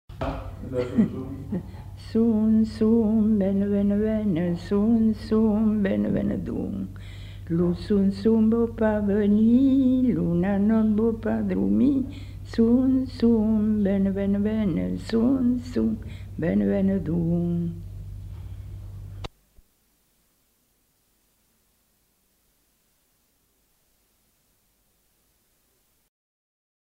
Aire culturelle : Bazadais
Lieu : Morizès
Genre : chant
Effectif : 1
Type de voix : voix de femme
Production du son : chanté
Classification : som-soms, nénies